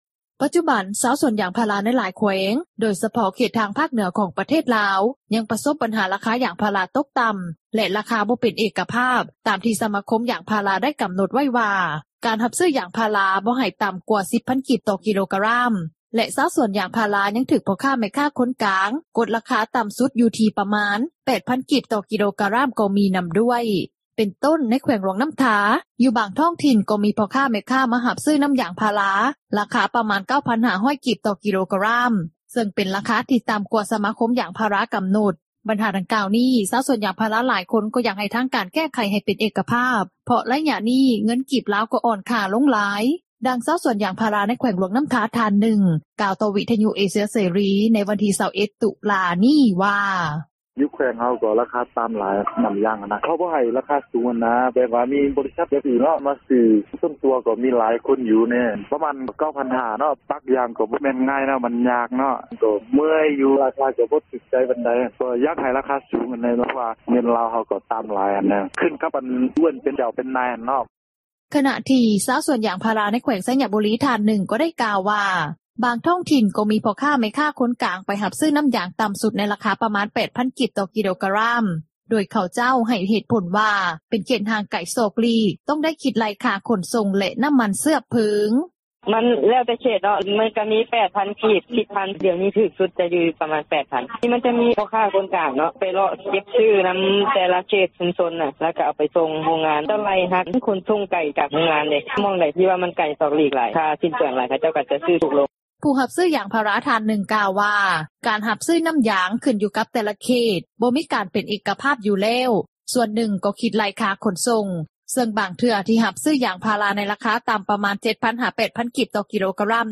ດັ່ງຊາວສວນຢາງພາຣາ ໃນແຂວງຫຼວງນໍ້າທາ ທ່ານນຶ່ງ ກ່າວຕໍ່ວິທຍຸ ເອເຊັຽເສຣີ ໃນວັນທີ 21 ຕຸລາ ນີ້ວ່າ: